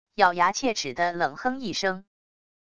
咬牙切齿的冷哼一声wav音频